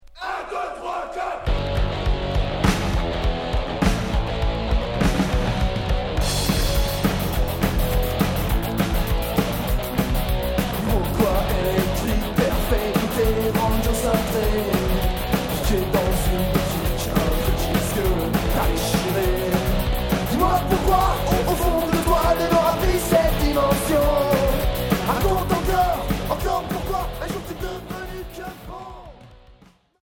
Punk rock Premier 45t